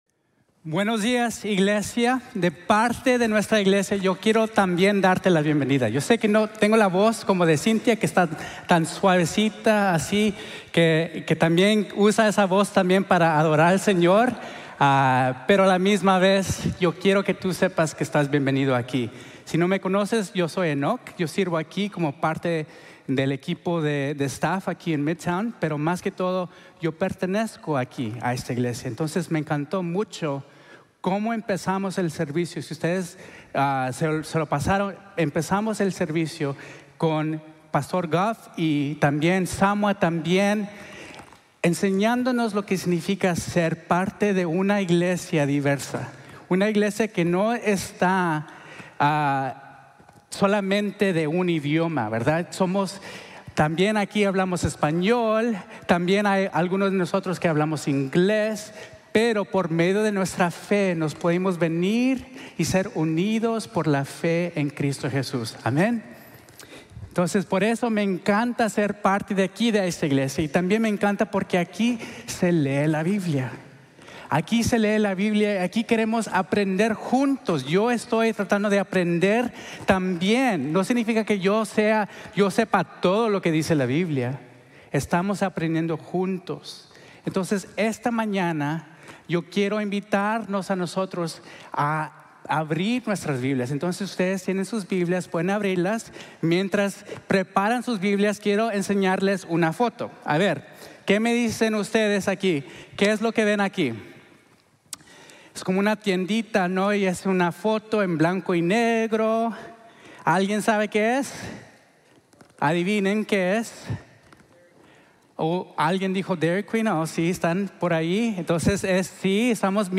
Enseñanzas del Discipulado | Sermon | Grace Bible Church